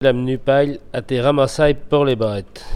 Langue Maraîchin
Catégorie Locution